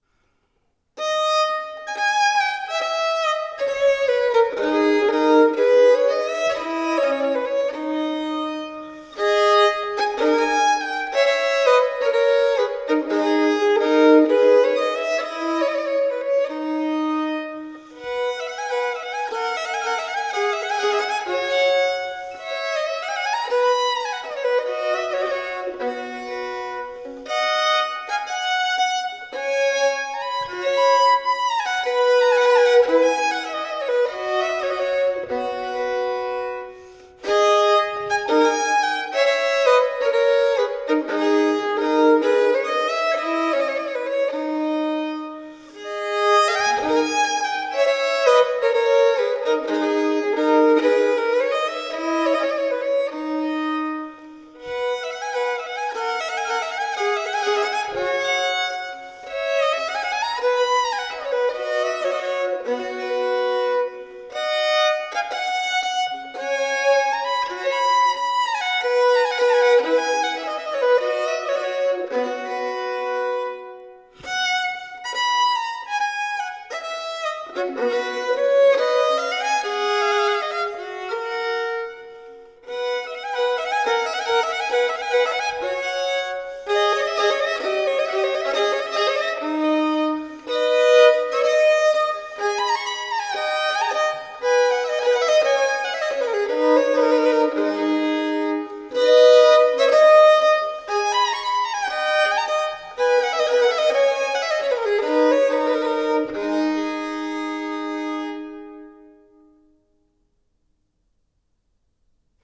Sonate per violino solo